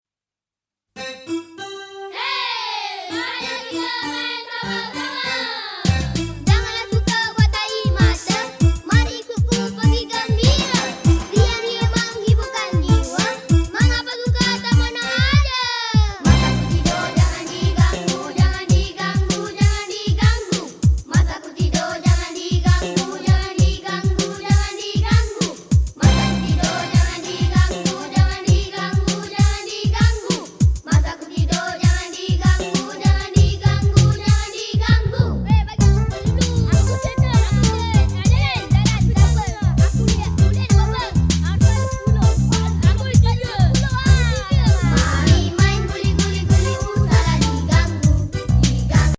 シンガポールの子供たちが歌う、キュートな東南アジア版セサミ・